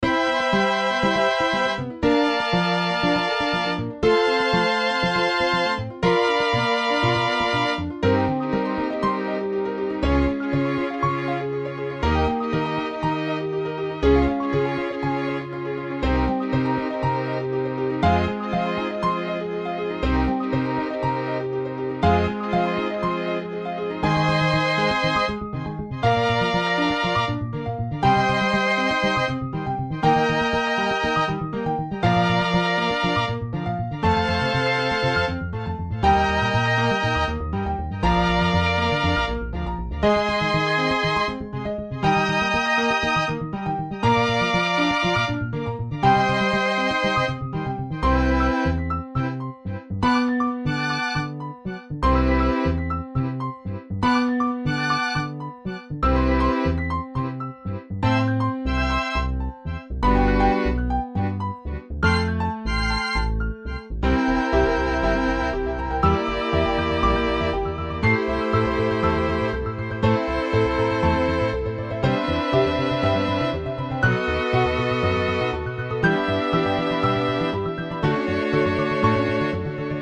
BGM
ピアノ、アコースティックベース、バイオリン